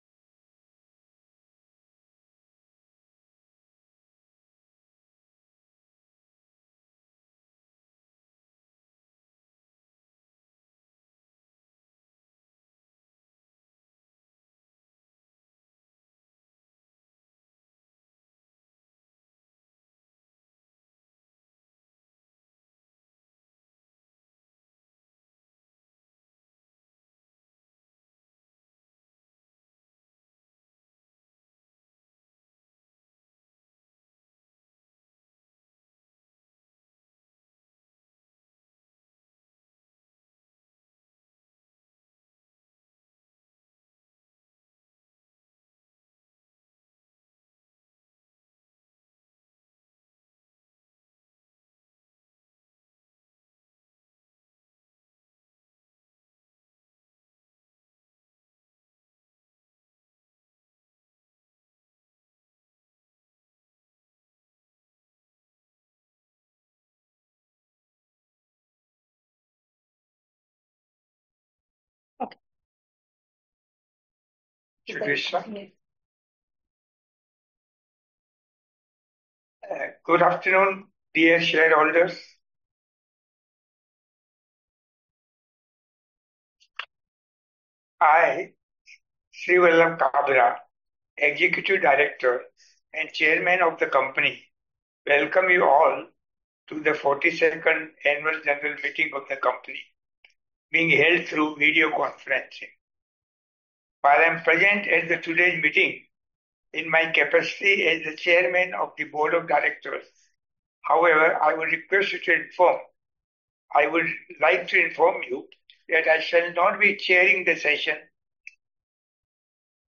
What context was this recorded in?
NSDL-42ND-ANNUAL-GENERAL-MEETING.mp3